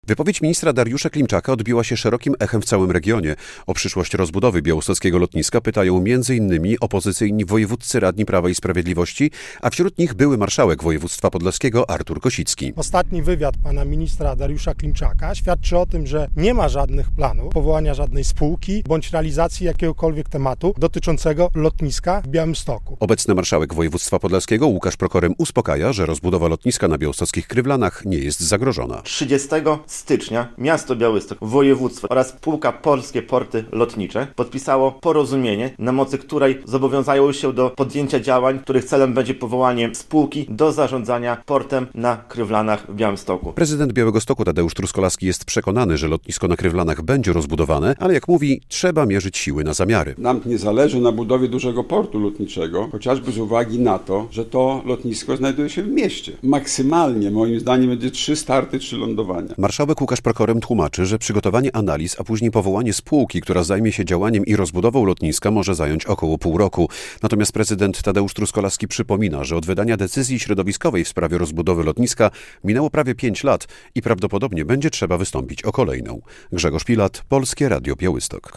Politycy PiS oraz marszałek i prezydent o lotnisku na Krywlanach - relacja